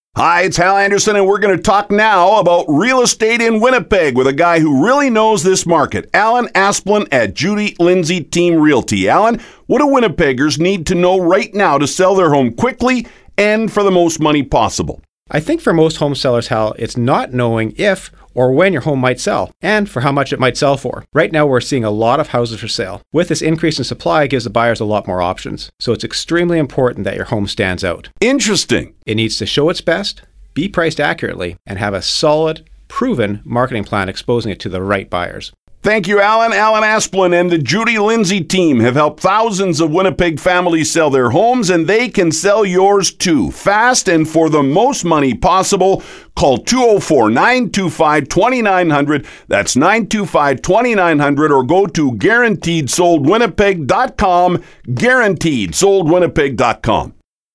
Interview 2